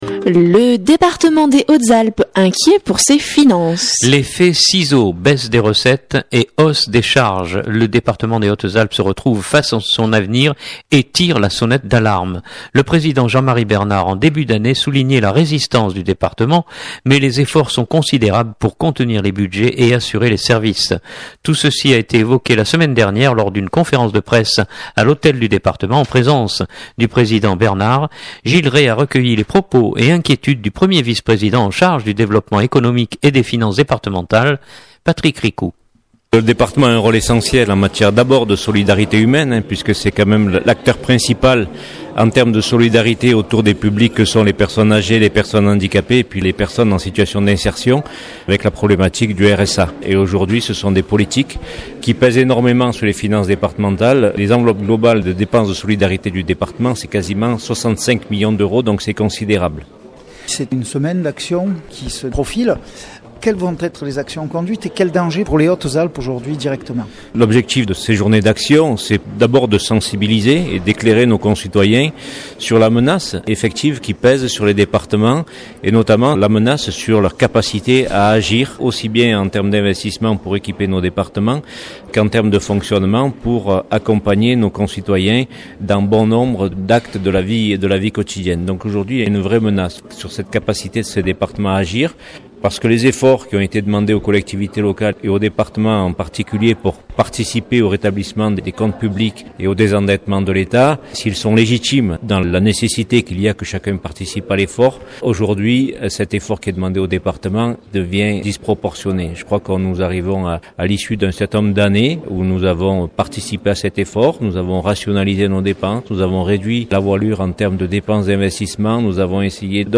Tout ceci a été évoqué la semaine dernière lors d’une conférence de presse à l’hôtel du département en présence du Président Bernard.